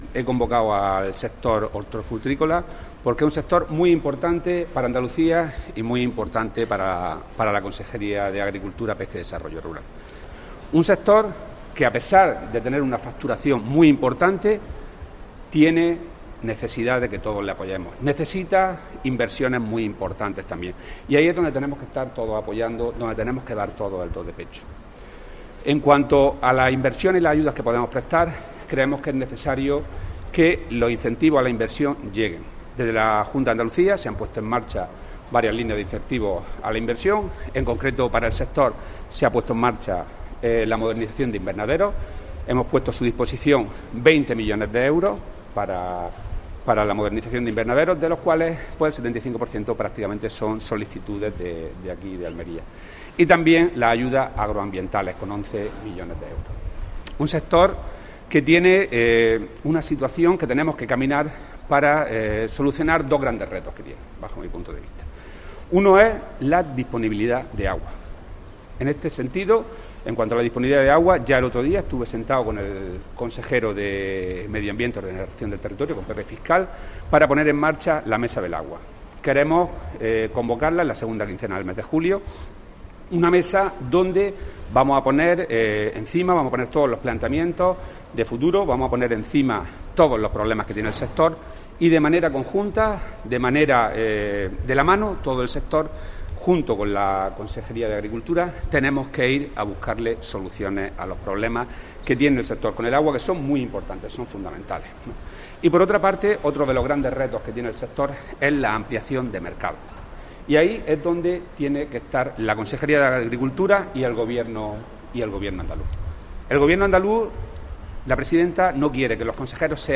Declaraciones de Rodrigo Sánchez sobre el sector hortofrutícola de Almería